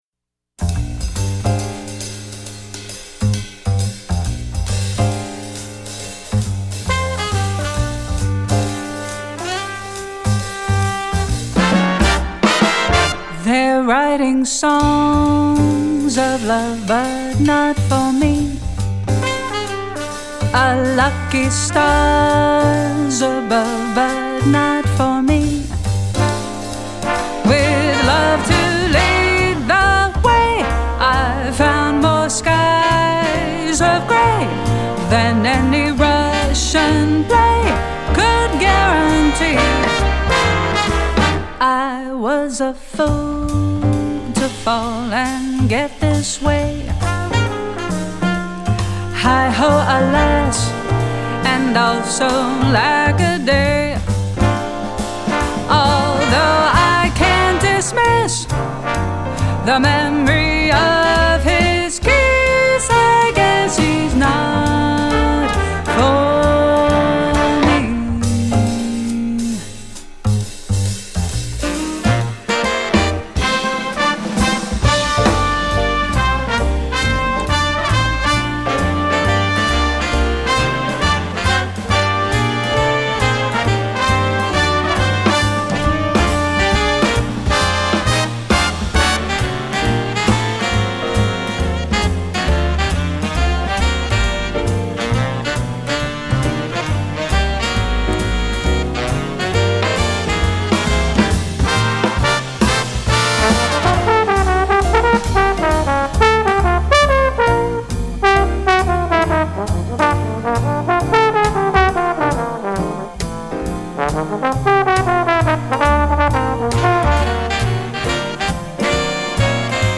broadway, jazz, film/tv, movies